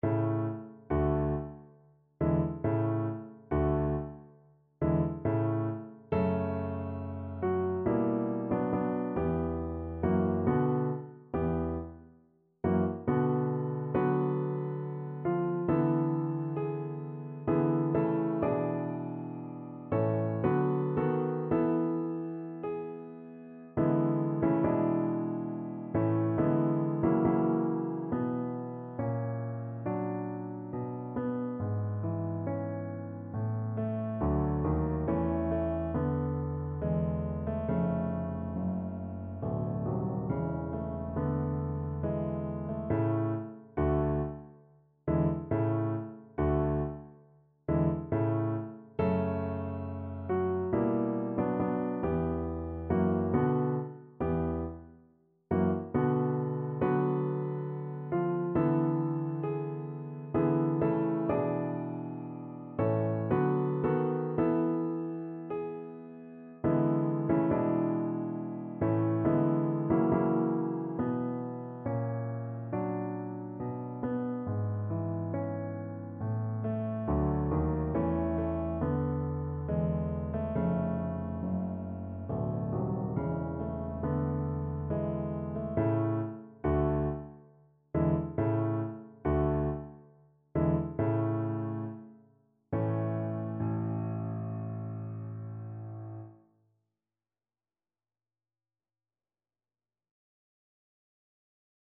3/4 (View more 3/4 Music)
Larghetto =69
Classical (View more Classical Cello Music)